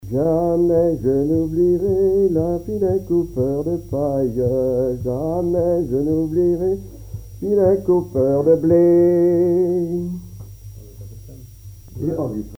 Divertissements d'adultes - Couplets à danser
danse : branle : courante, maraîchine
Enquête Arexcpo en Vendée
Pièce musicale inédite